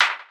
Teck-perc (where).wav